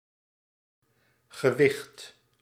Ääntäminen
Tuntematon aksentti: IPA: /ɣəˈʋɪxt/